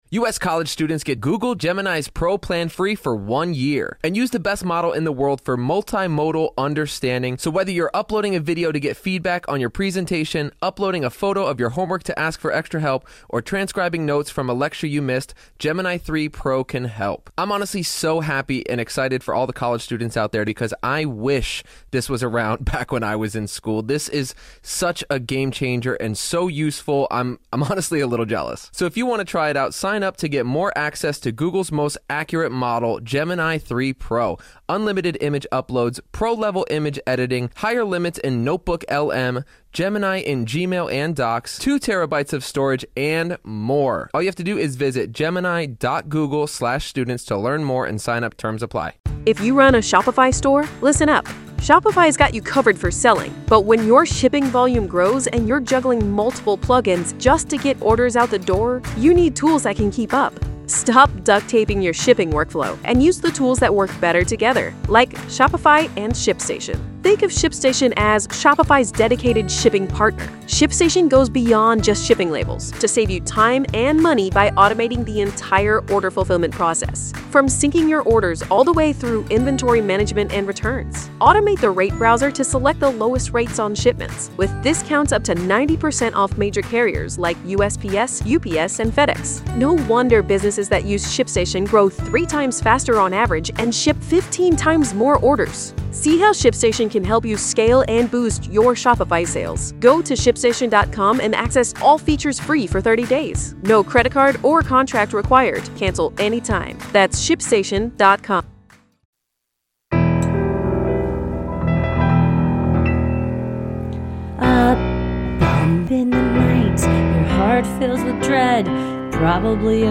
Bonus: A Conversation